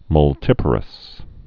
(mŭl-tĭpər-əs)